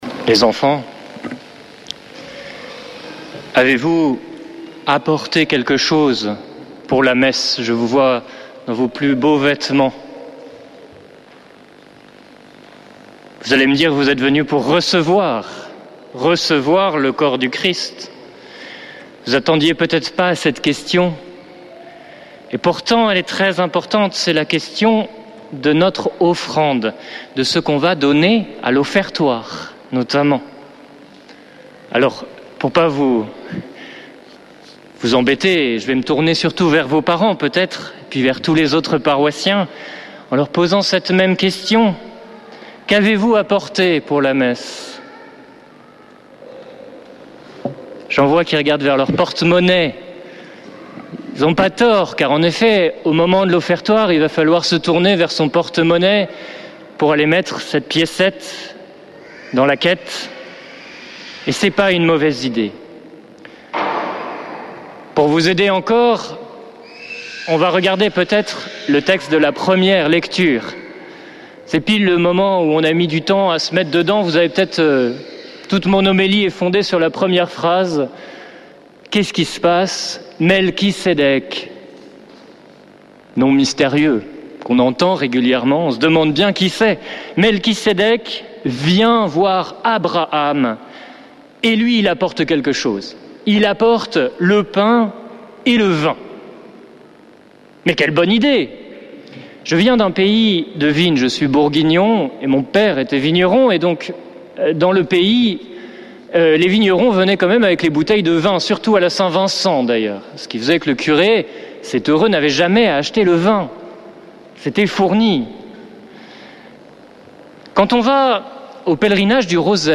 Accueil \ Emissions \ Foi \ Prière et Célébration \ Messe depuis le couvent des Dominicains de Toulouse \ « Quelle est mon offrande ?